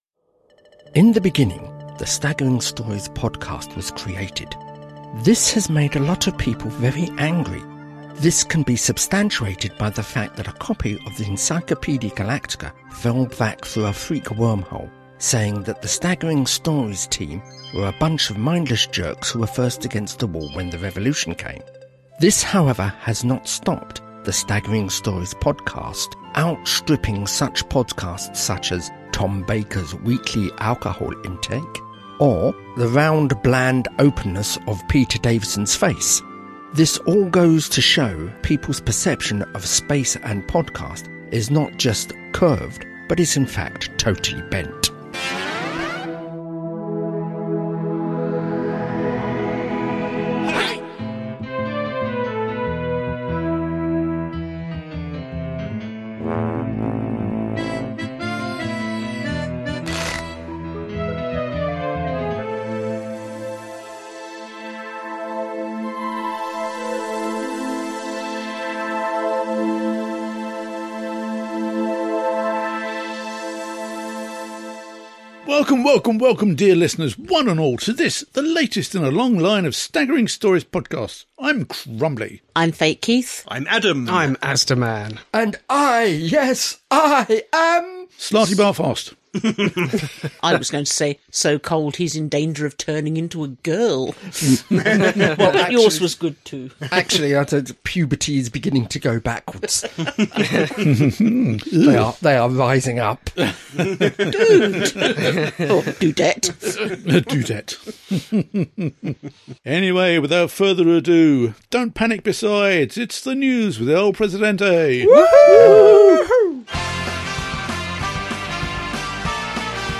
00:00 – Intro and theme tune.
54:28 — End theme, disclaimer, copyright, etc.